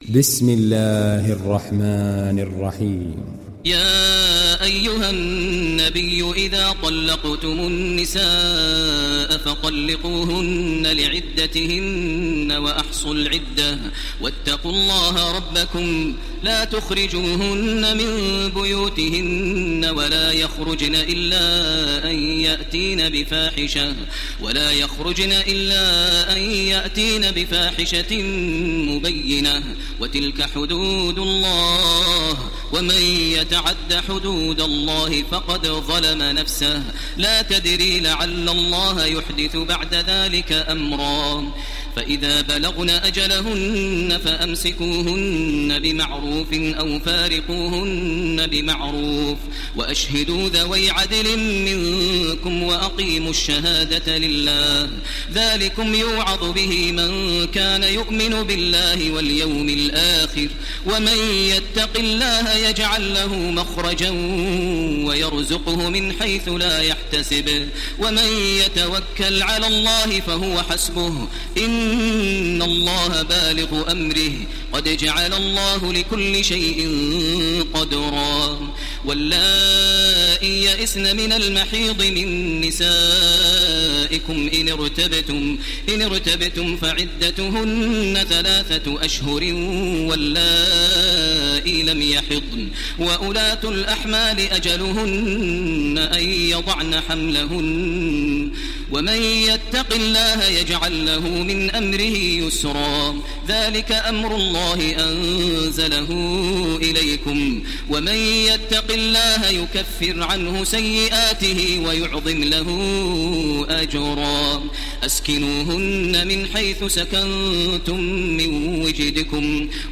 Télécharger Sourate At Talaq Taraweeh Makkah 1430